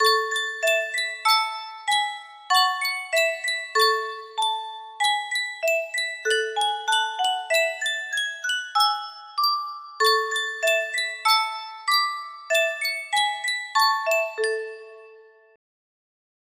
Sankyo Boite a Musique - Au clair de la lune CVQ music box melody
Full range 60